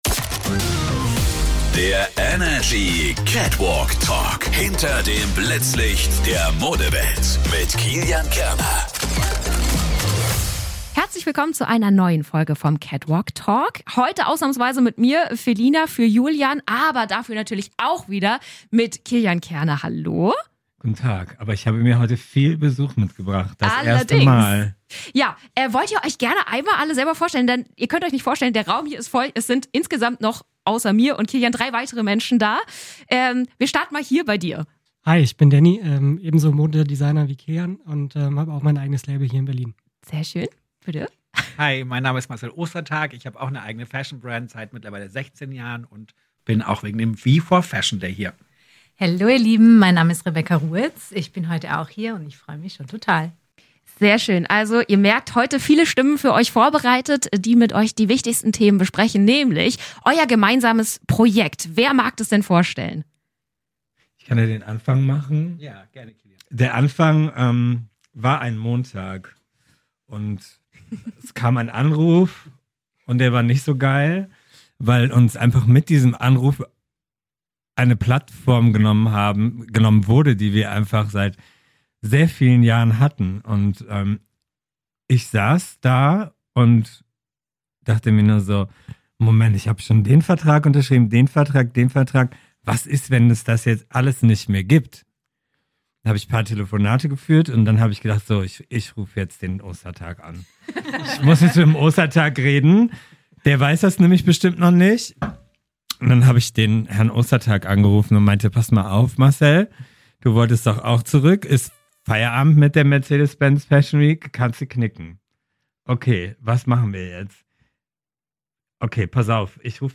Vier der größten Designer Deutschlands schließen sich zusammen für einen Tag mit 4 exklusiven Shows: Den W.E4. FASHION DAY. Und wir haben sie im Studio